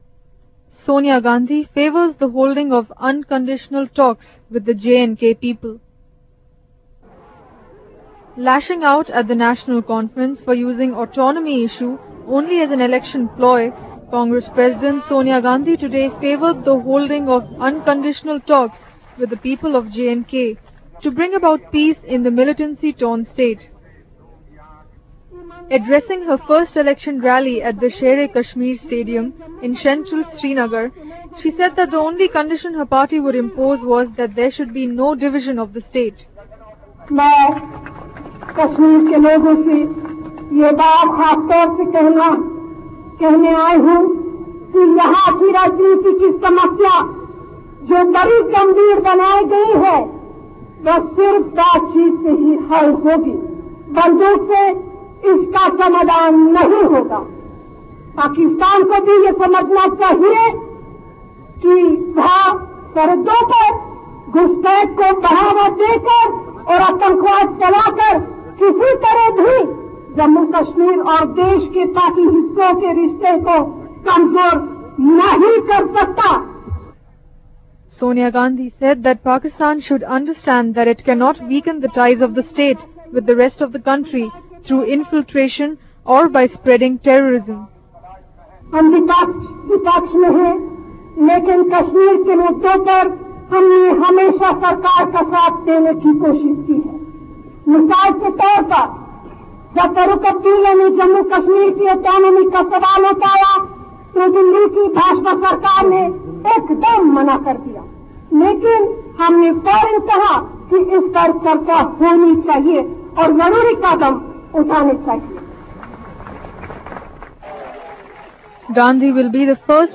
Congress President Sonia Gandhi waves to her masked supporters (right) during an election campaign rally in Srinagar on Thursday.